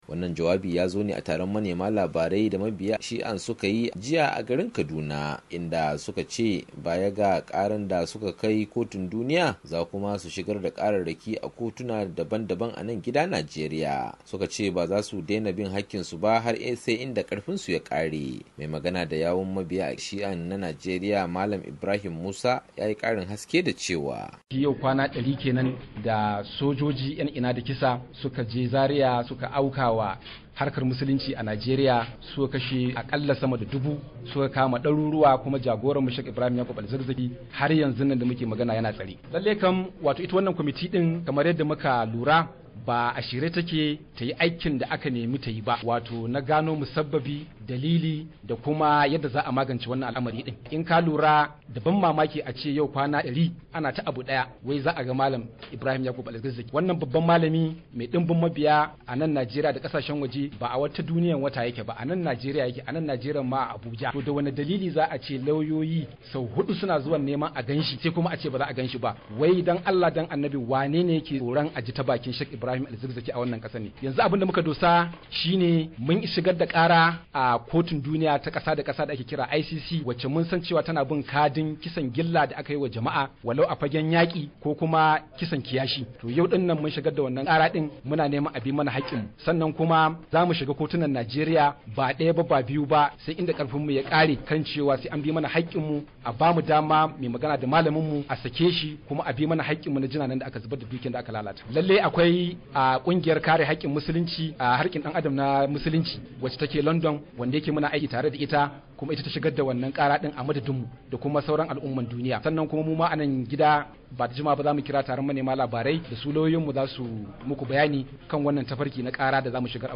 ga rahoto wakilin mu a Abuja